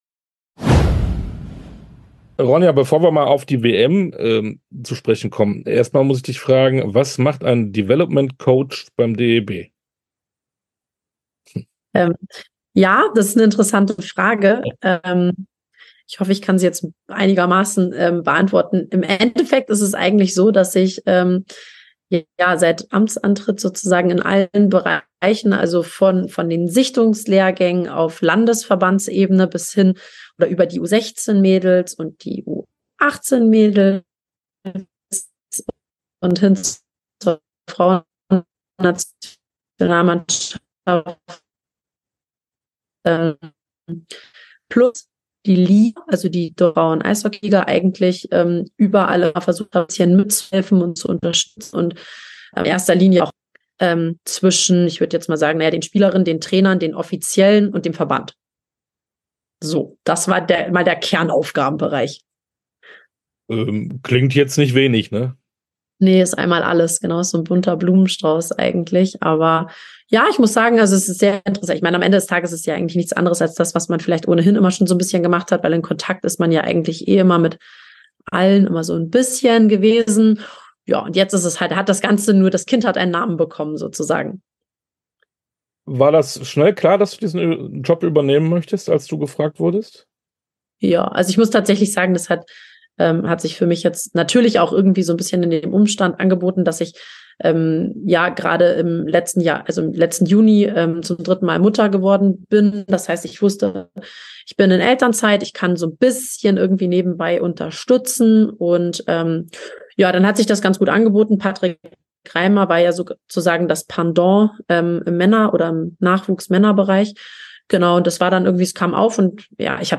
Interview komplett